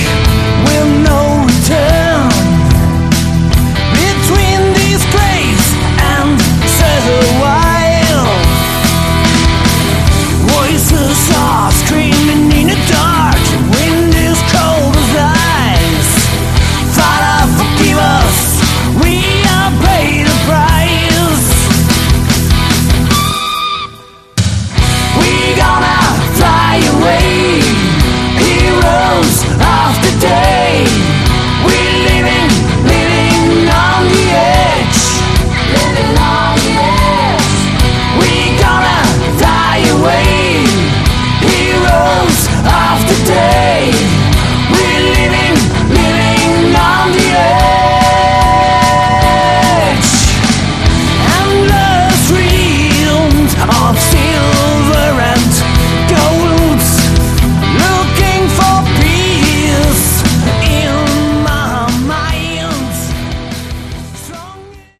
Category: melodic hard rock
Vocals
Guitars
Bass
Keyboards
Drums